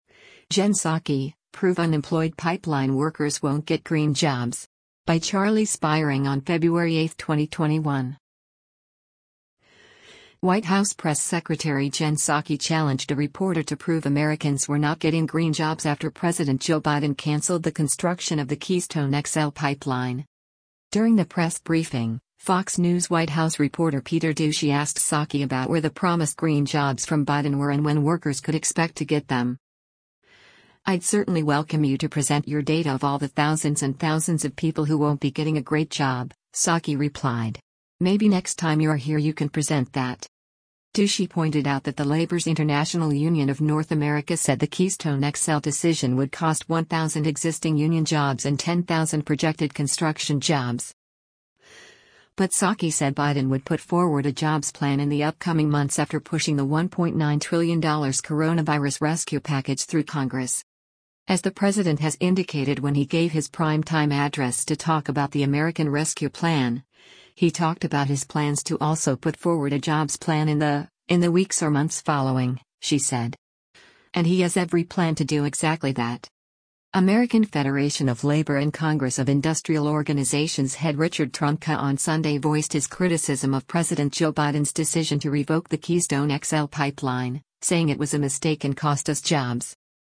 Biden White House Press Secretary Jen Psaki participates in a White House press briefing a
During the press briefing, Fox News White House reporter Peter Doocy asked Psaki about where the promised “green jobs” from Biden were and when workers could expect to get them.